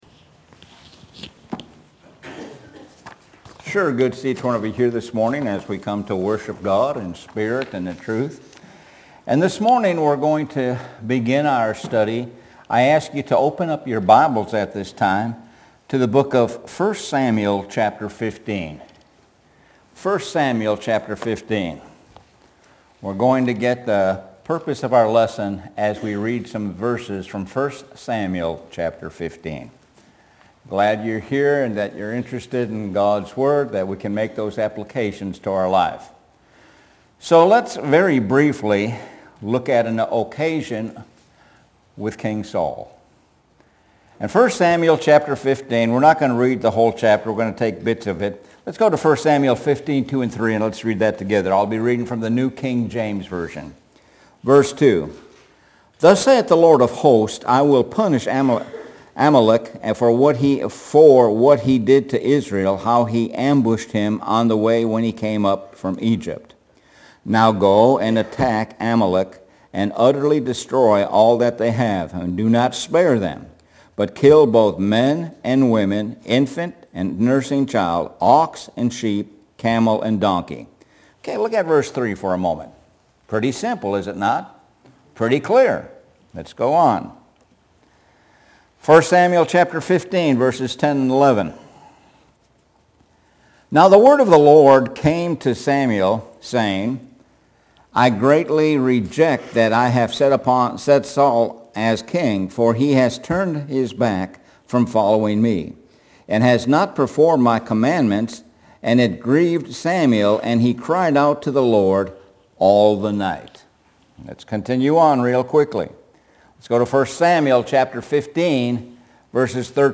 Sun AM Sermon – Accountability – 2.2.2025